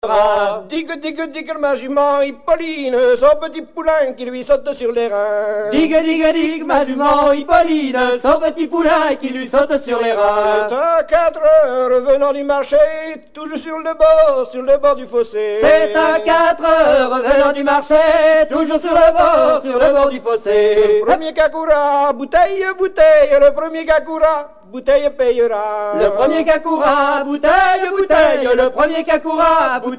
Danse : Rond de Saint-Vincent